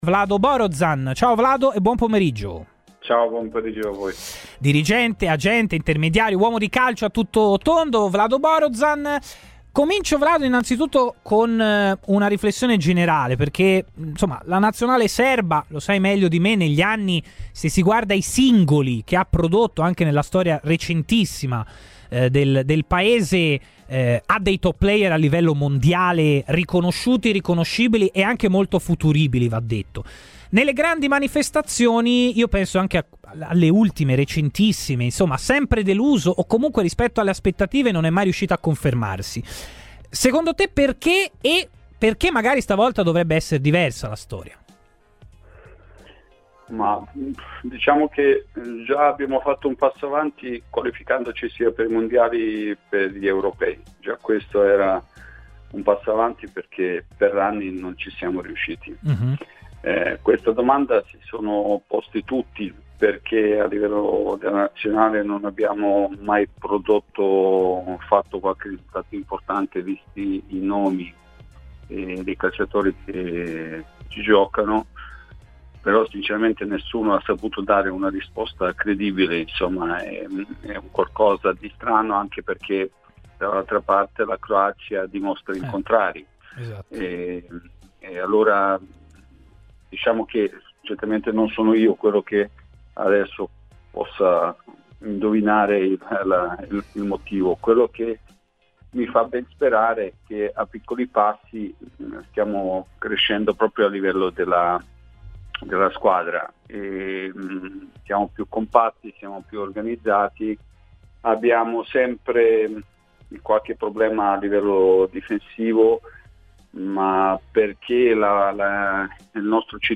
intervenuto ai microfoni di Radio FirenzeViola durante la trasmissione.